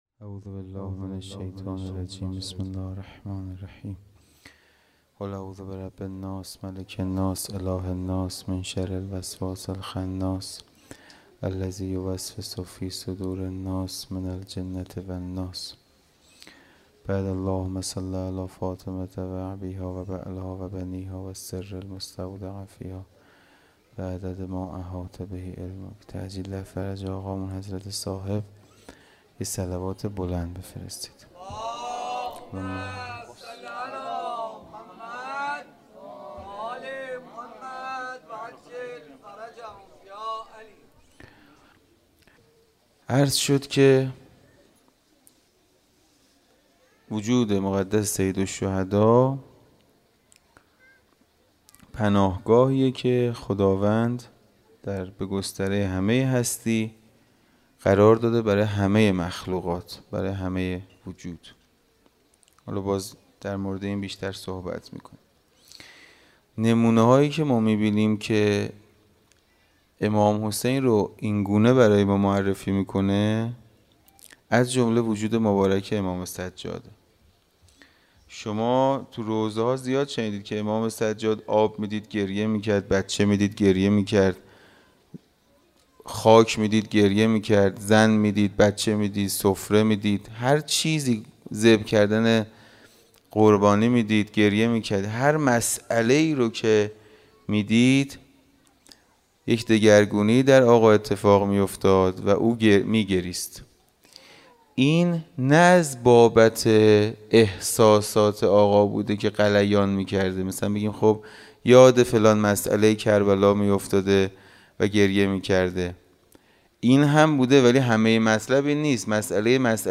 خیمه گاه - حسینیه کربلا - شب ششم محرم- سخنرانی